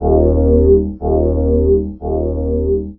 wetsuit2.wav